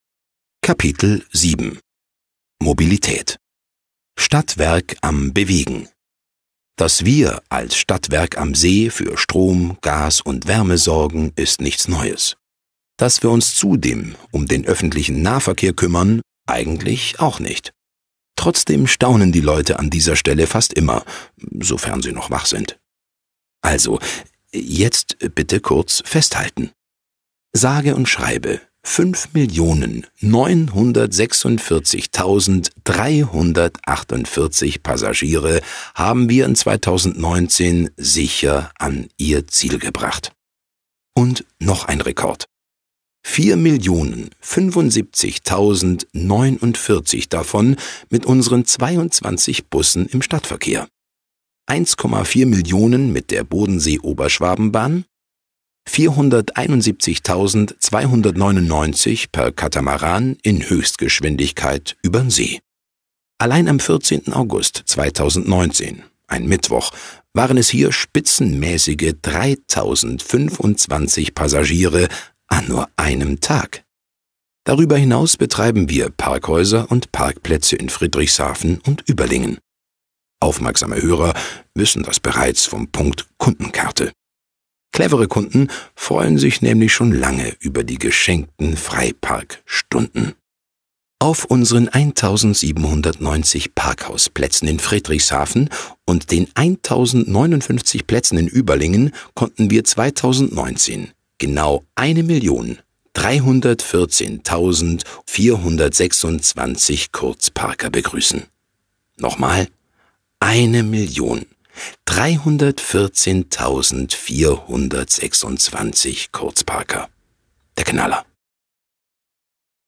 Genau richtig – zum Runterfahren und Entspannen.